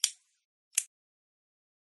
Descarga de Sonidos mp3 Gratis: encendedor 5.
lighter-strike.mp3